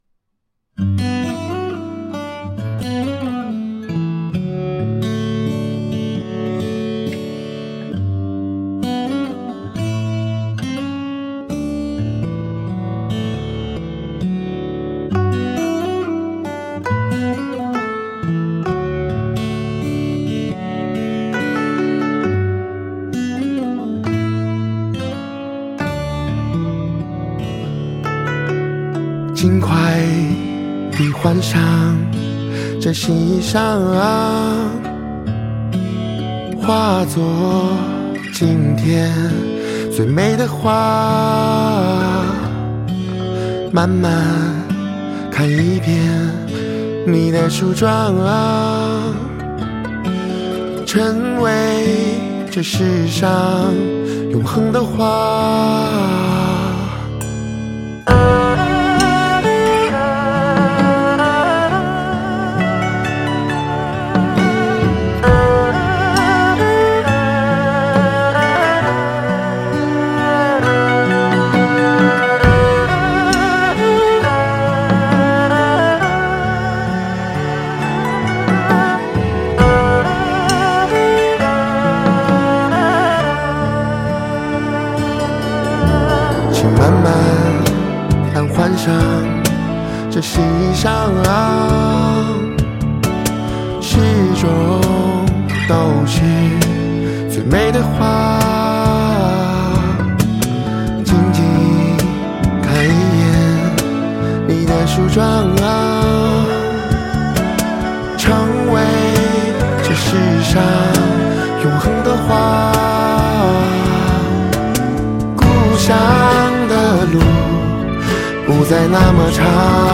木吉他
电吉他
贝司
曼陀铃
二胡